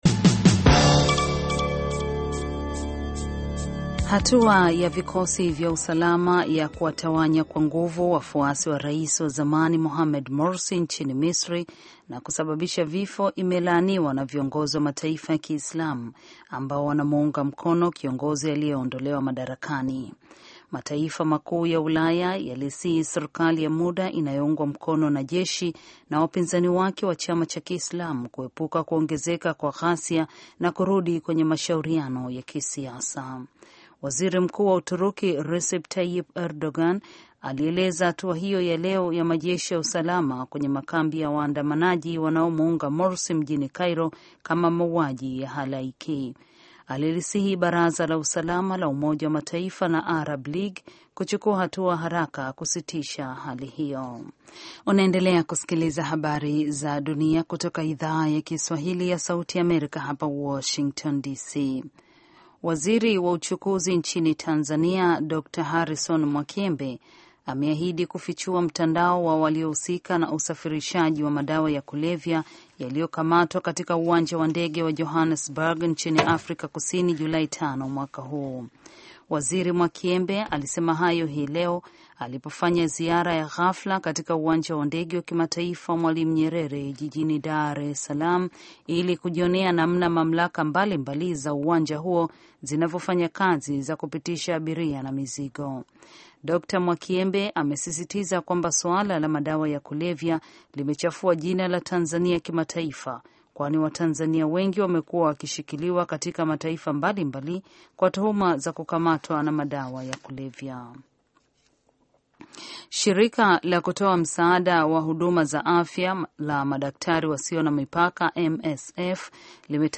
Taarifa ya Habari VOA Swahili - 7:50